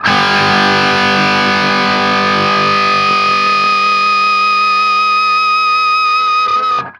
TRIAD G   -R.wav